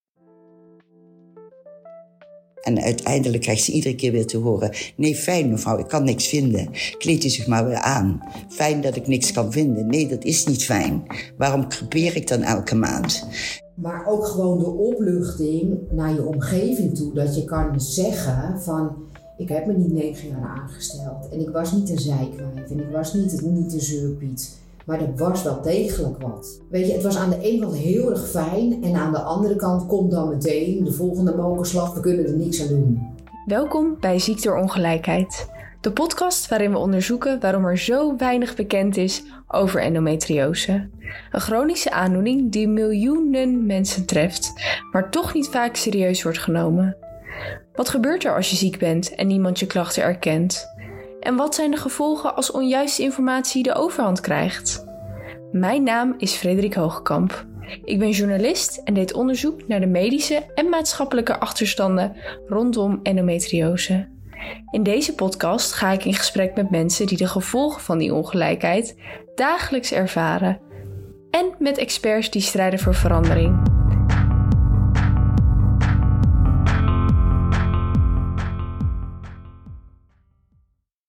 In deze serie hoor je de verhalen van vrouwen die jarenlang moesten vechten voor een diagnose, zorgverleners die zich uitspreken tegen desinformatie, en specialisten die laten zien hoe hardnekkig de ongelijkheid in de gezondheidszorg is. Waarom worden menstruatieklachten nog steeds gebagatelliseerd?